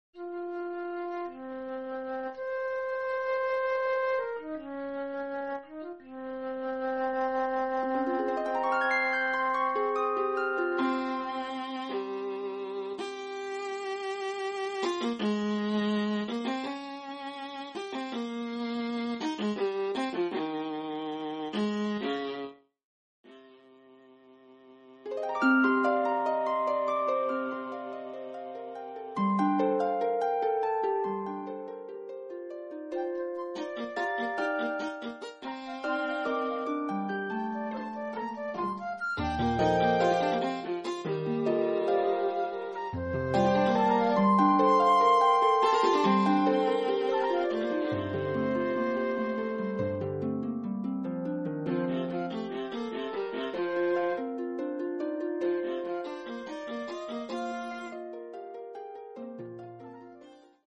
Calmo, Animato